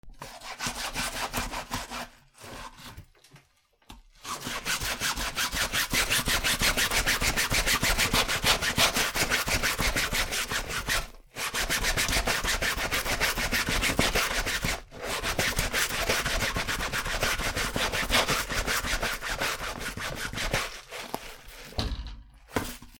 靴を洗う 家事
/ J｜フォーリー(布ずれ・動作) / J-05 ｜布ずれ